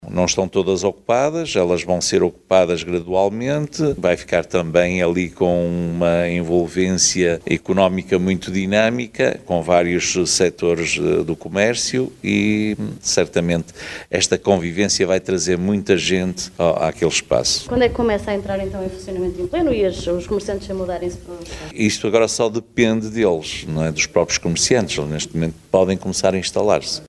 As obras começaram em novembro de 2021 e agora o mercado já pode voltar ao seu funcionamento em pleno, refere o presidente da autarquia, Benjamim Rodrigues: